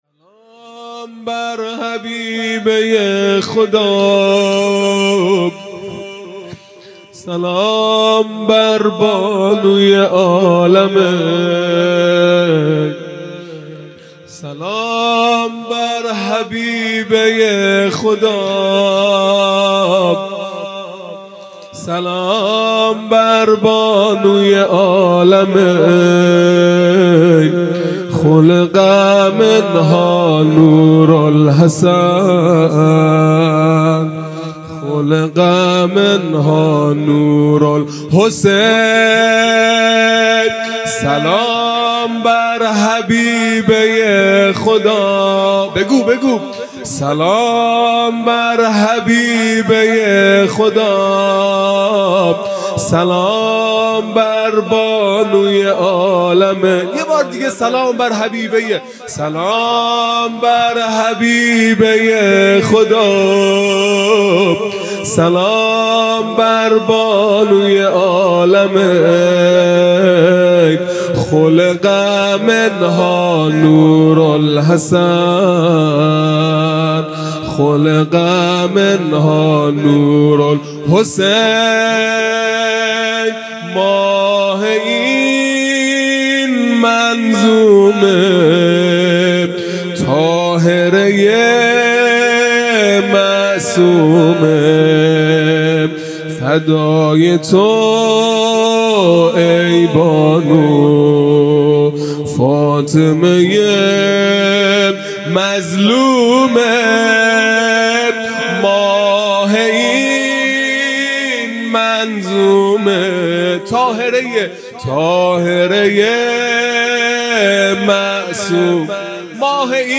خیمه گاه - هیئت بچه های فاطمه (س) - زمینه | سلام بر حبیبه خدا
عزاداری فاطمیه اول | شب اول